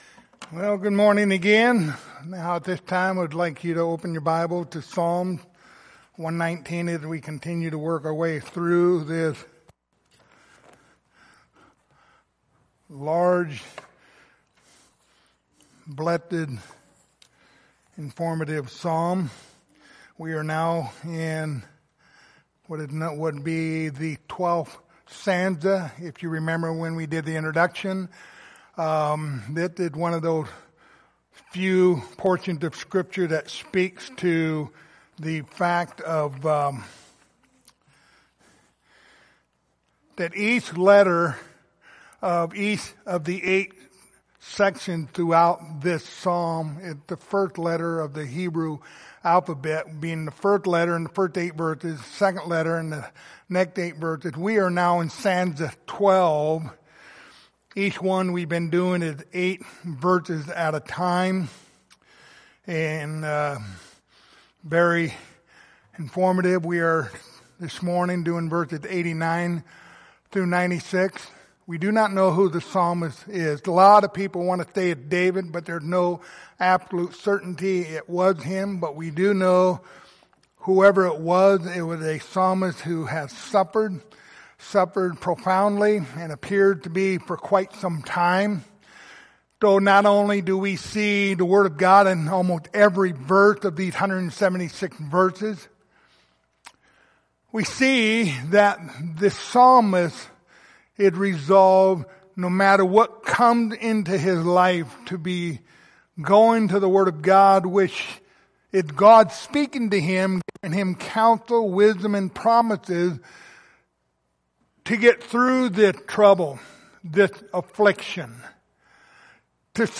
The book of Psalms Passage: Psalm 119:89-96 Service Type: Sunday Morning Topics